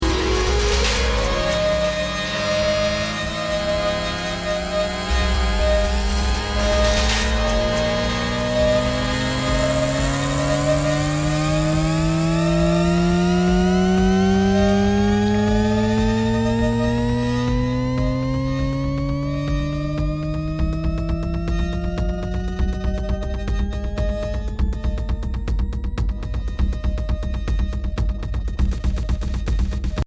Sounds, rhythmic noise, and atmospheric constructions.
Making use of the audio phenomenon known as a Shepard Tone.